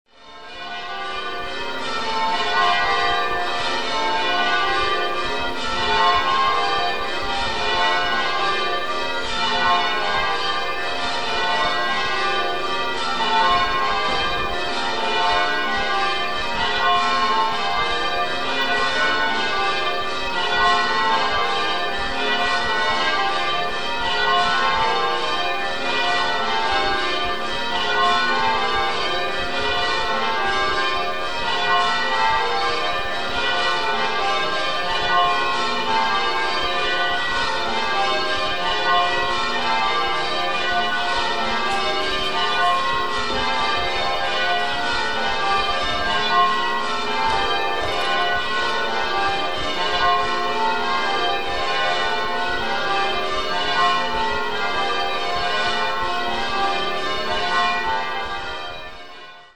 Hear the bells – Ringing call changes on 6